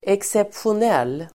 Ladda ner uttalet
Uttal: [eksepsjon'el:]